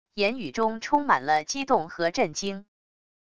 言语中充满了激动和震惊wav音频